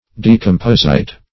Decomposite \De`com*pos"ite\, n.